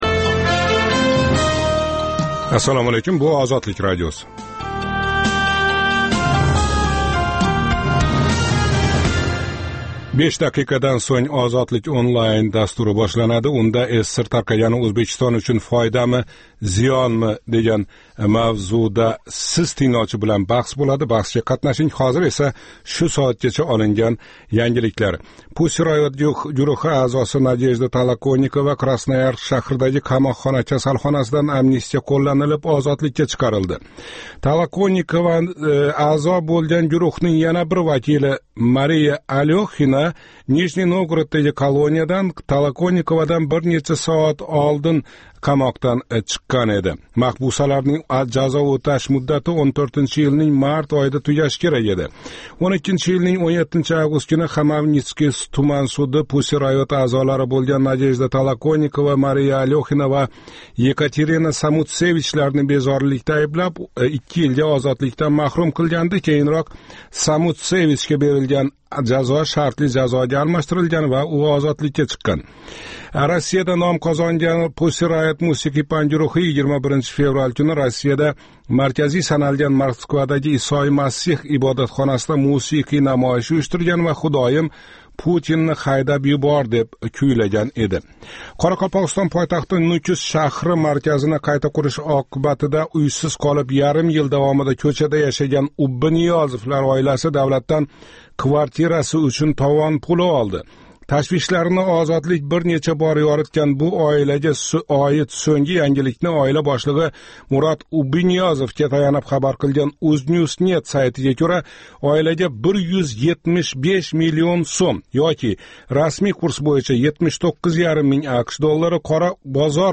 “Ozodlik Online” - Интернетдаги энг замонавий медиа платформаларни битта тугал аудио дастурга бирлаштирган Озодликнинг жонли интерактив лойиҳаси.